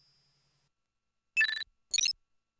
inventory_hum.wav